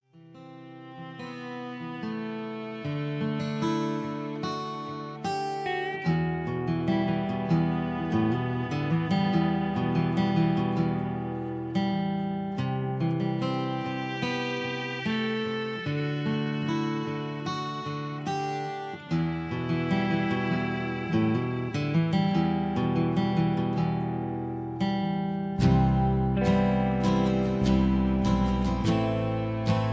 Key-Em) Karaoke MP3 Backing Tracks
Just Plain & Simply "GREAT MUSIC" (No Lyrics).